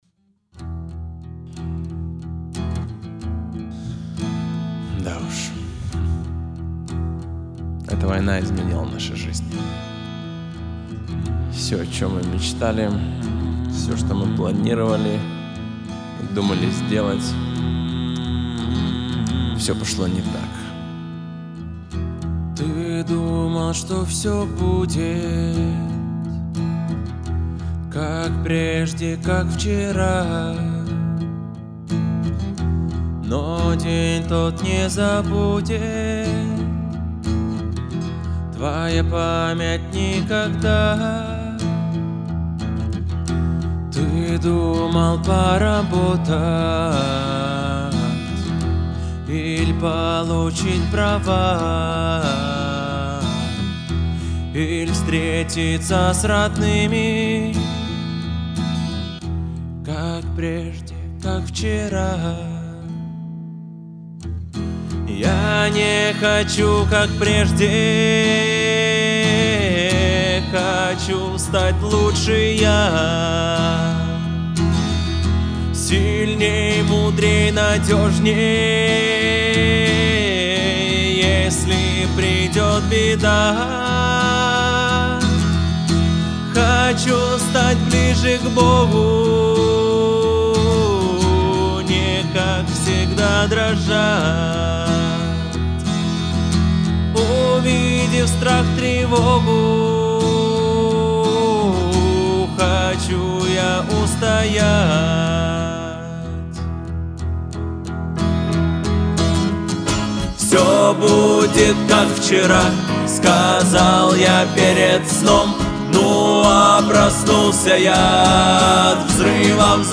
161 просмотр 85 прослушиваний 6 скачиваний BPM: 90